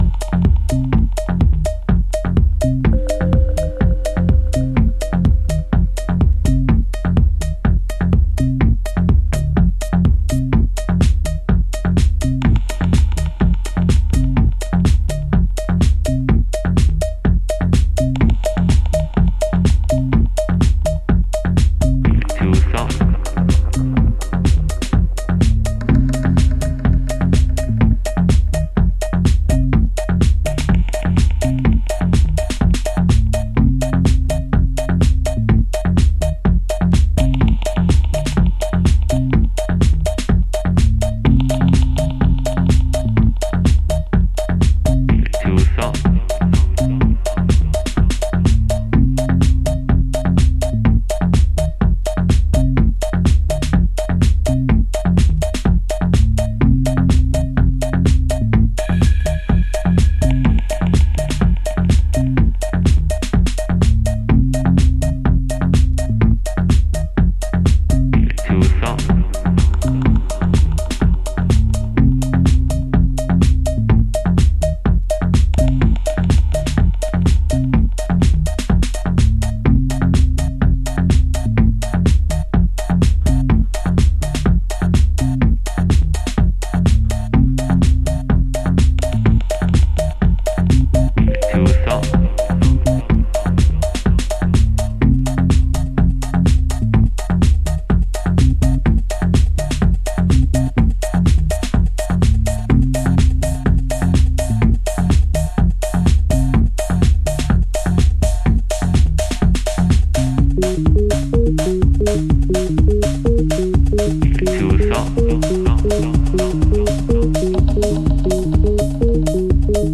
STRANGE MINIMAL HOUSE
House / Techno